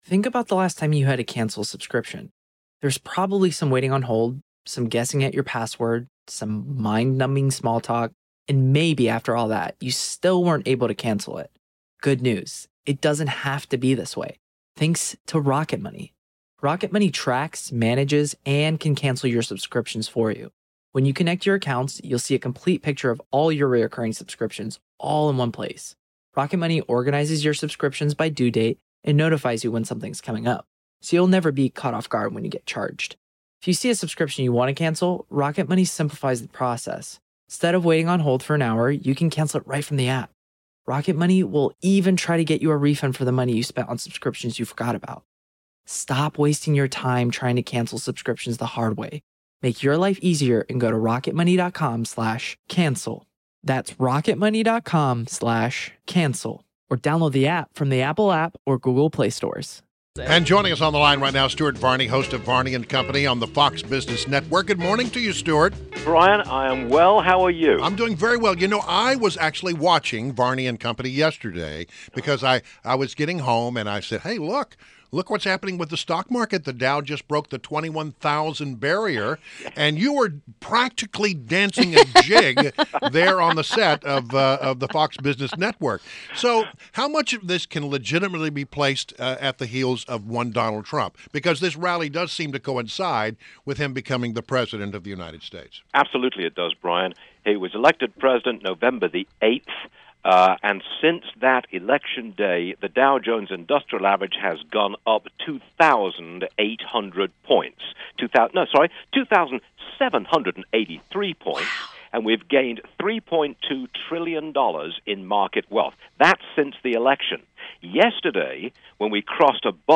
INTERVIEW — STUART VARNEY – Host of “Varney and Company” on FOX BUSINESS NETWORK